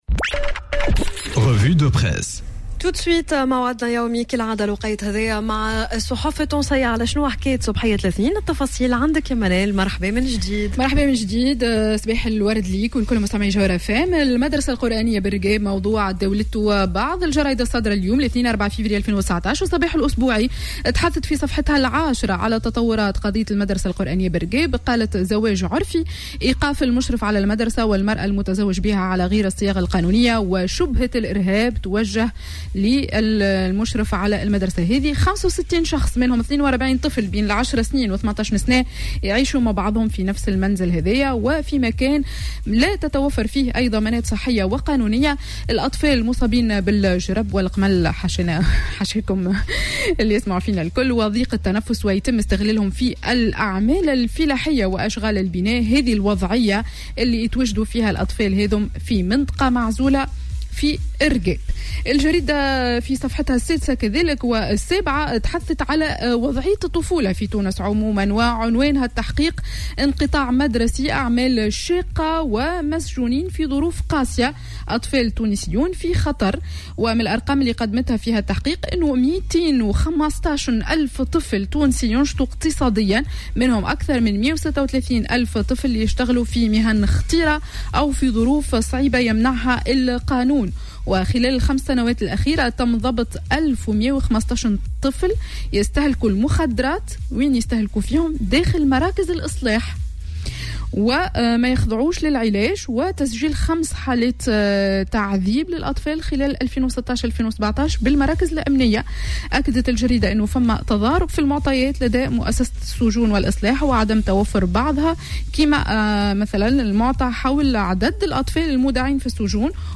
Revue de presse du lundi 04 Février 2019